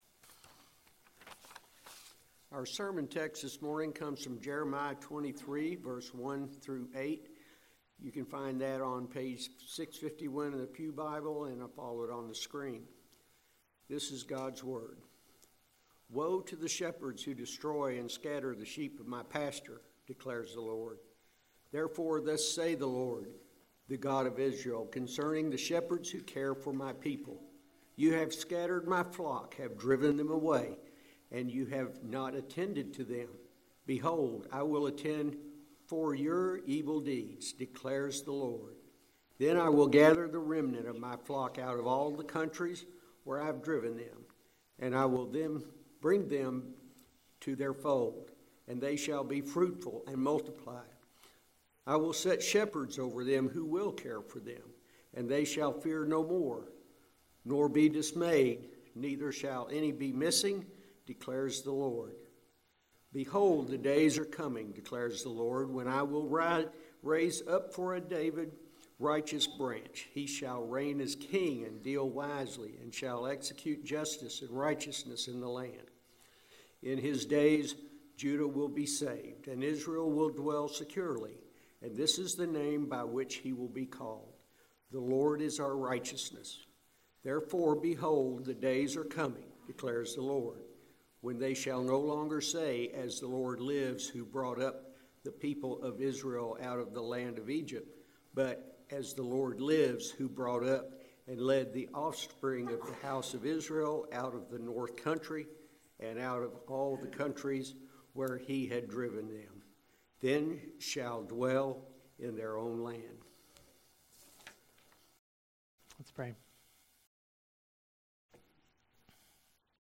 December 17, 2017 Morning Worship | Vine Street Baptist Church
He welcomed guests and visitors to today’s service, then everyone spent a few minutes greeting one another.
After the sermon, everyone stood and sang Come Thou Long Expected Jesus during a time of reflection.